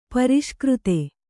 ♪ pariṣkřte